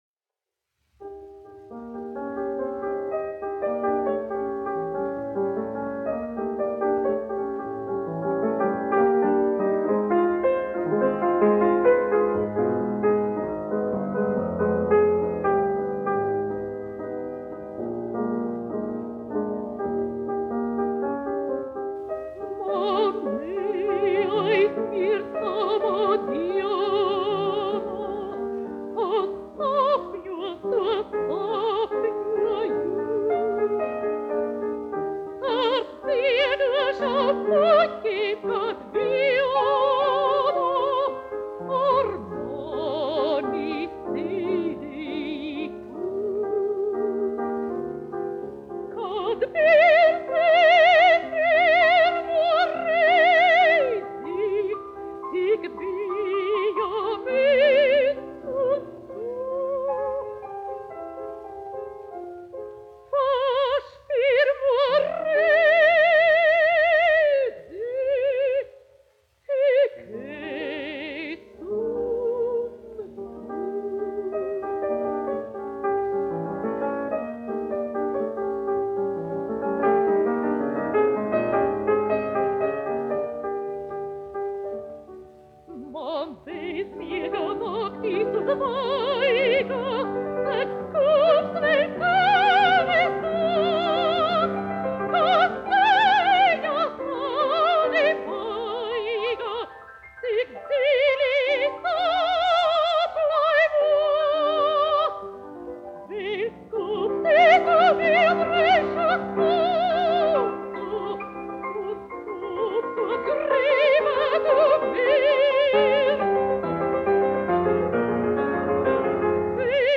1 skpl. : analogs, 78 apgr/min, mono ; 25 cm
Dziesmas (vidēja balss) ar klavierēm
Skaņuplate